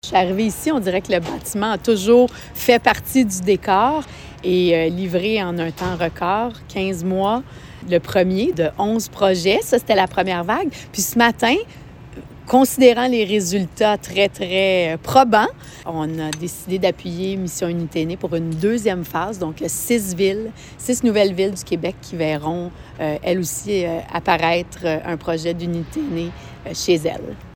La ministre responsable de l’Habitation, Mme France-Élaine Duranceau, a rappelé le caractère inédit et les avantages considérables de ce partenariat.